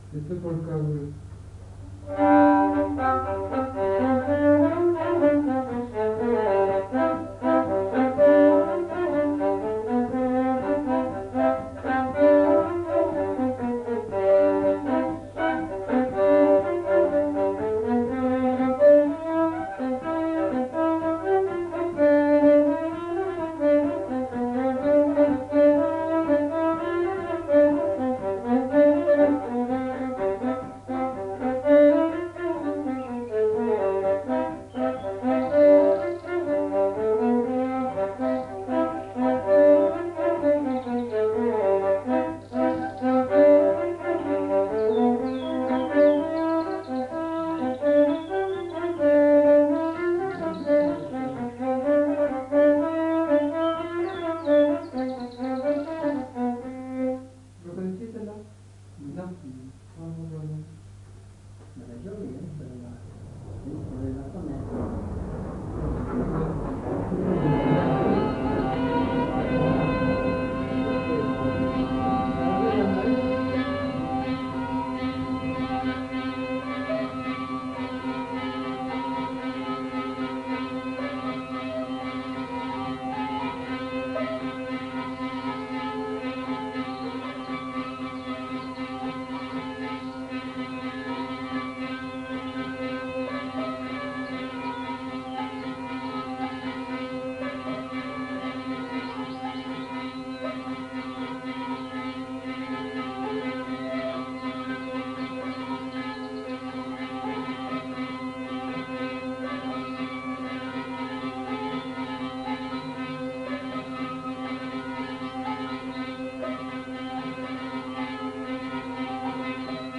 Aire culturelle : Petites-Landes
Lieu : Lencouacq
Genre : morceau instrumental
Instrument de musique : vielle à roue ; violon
Danse : polka